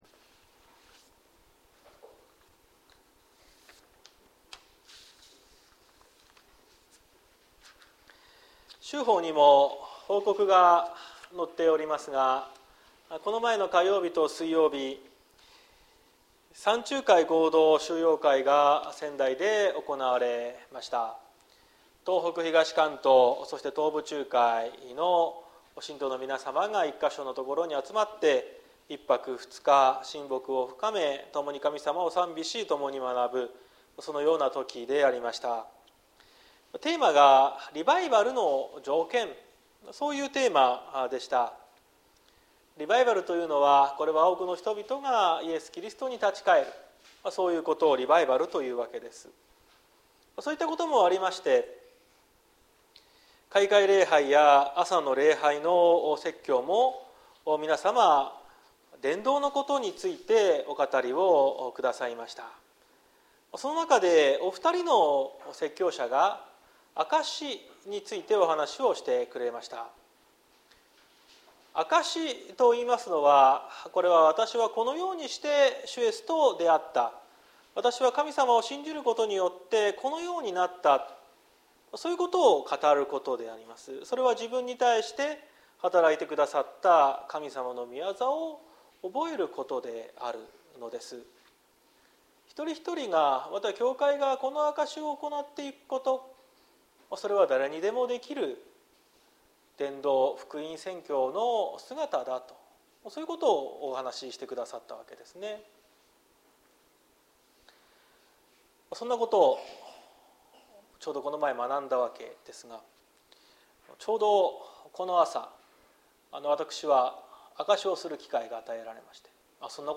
2023年08月06日朝の礼拝「罪人を招くイエス」綱島教会
綱島教会。説教アーカイブ。